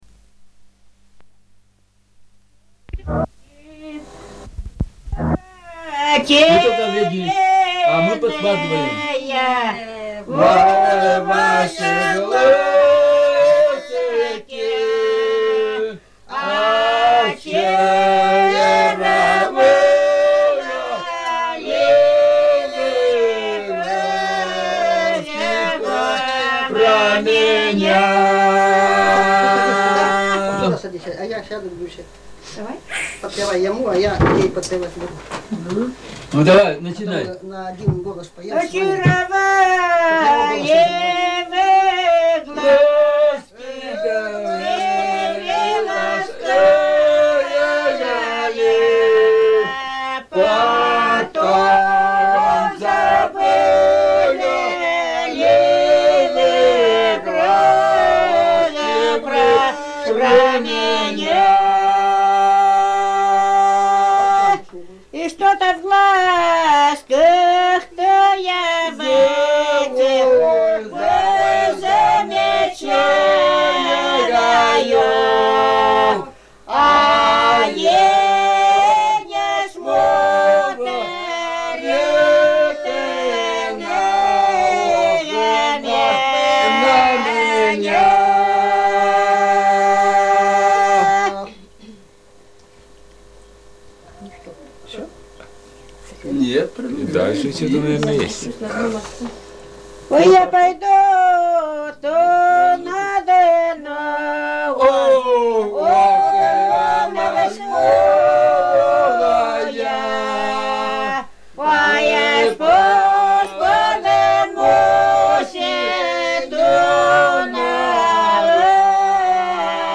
Старинные песни
"Очаровательные глазки" романс
с. Кежма,  1993г.